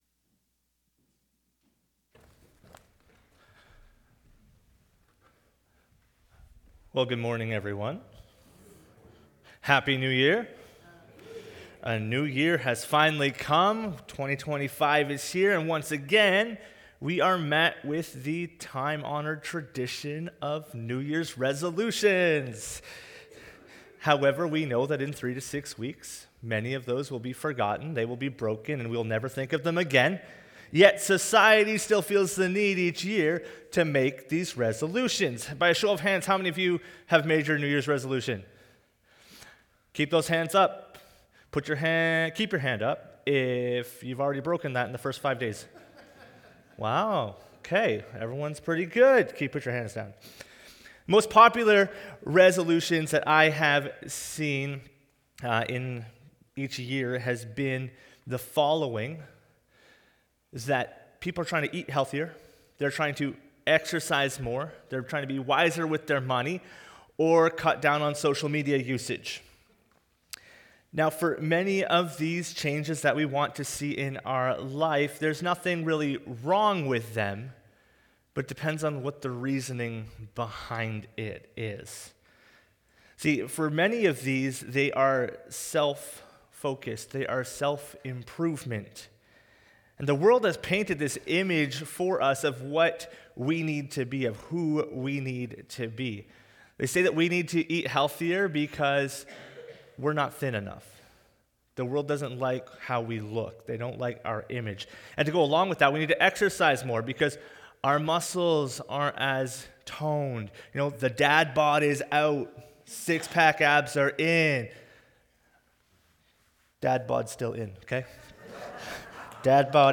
Download Download One-Off Sermons What Are You Looking For?